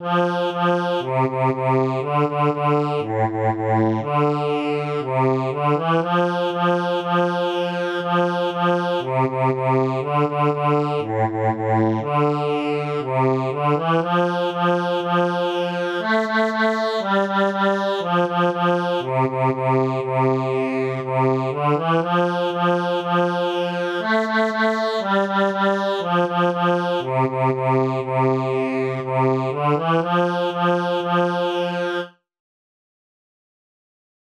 Jewish Folk Song (Chabad-Lubavitch melody)
F major ♩= 120 bpm